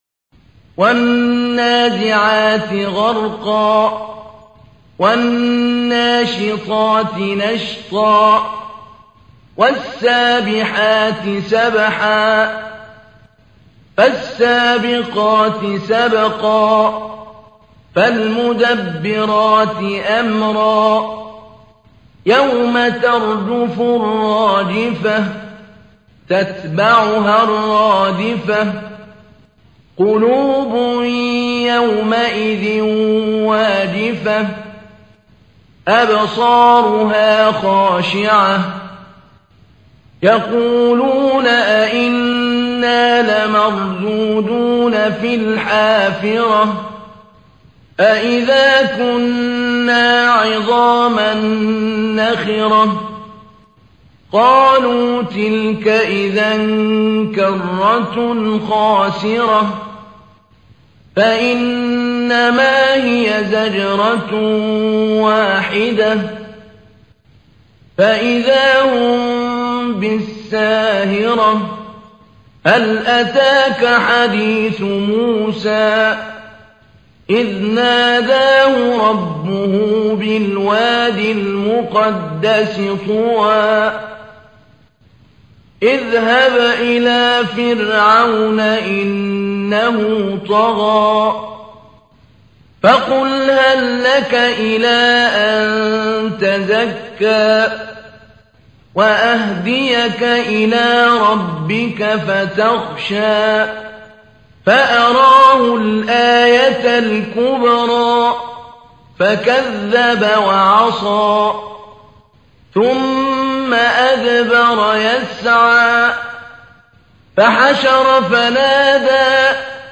تحميل : 79. سورة النازعات / القارئ محمود علي البنا / القرآن الكريم / موقع يا حسين